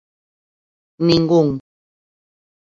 [niŋˈɡuŋ]